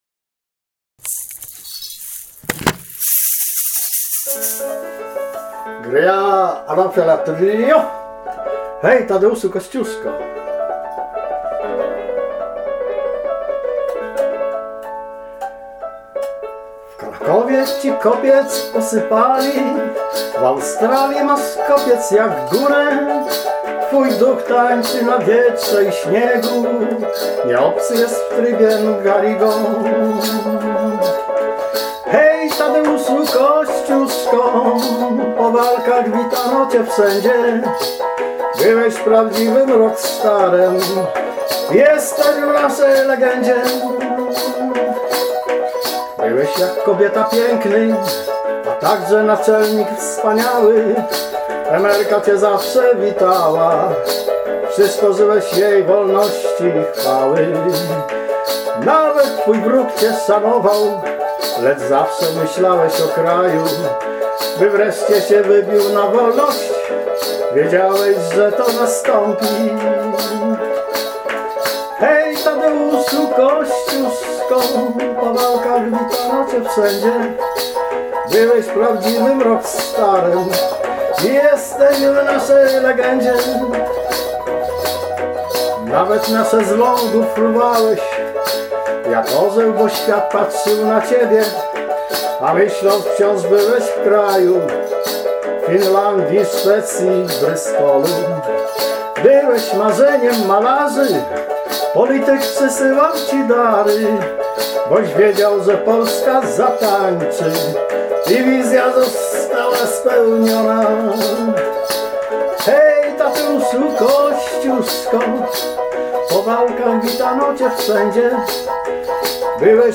Zapraszamy do posłuchania dwóch wesołych piosenek o Kosciuszce w wyk.